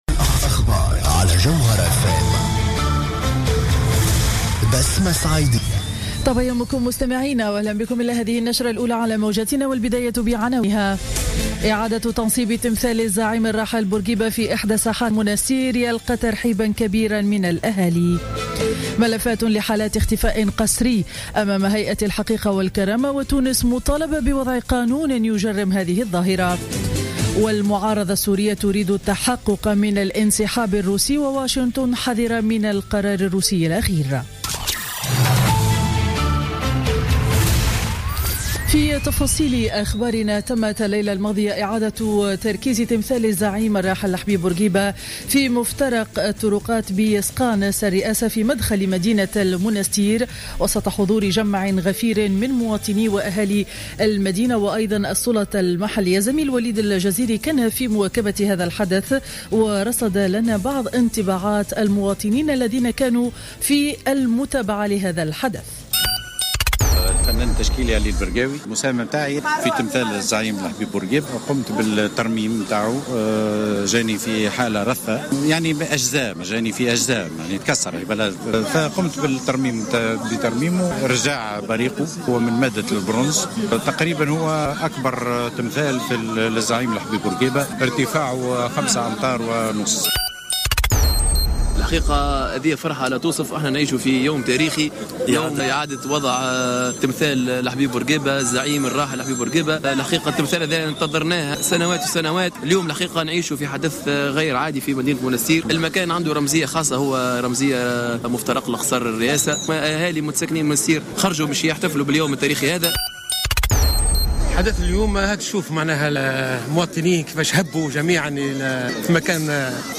نشرة أخبار السابعة صباحا ليوم الثلاثاء 15 مارس 2016